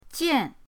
jian4.mp3